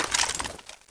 weaponpickup.wav